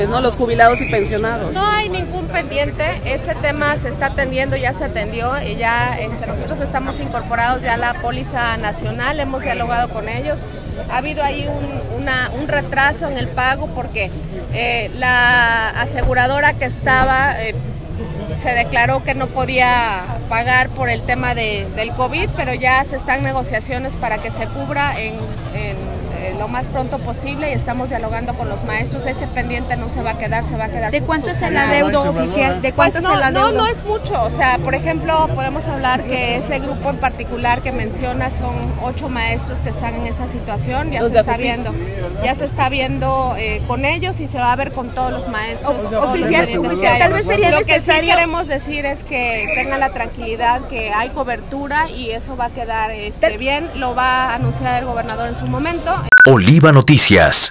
En entrevista, dijo que hubo problemas con la aseguradora que tenían, ya que durante la pandemia declaró que ya no podía pagar, sin embargo, el tema ya se atendió y están en negociaciones para pagar el recurso.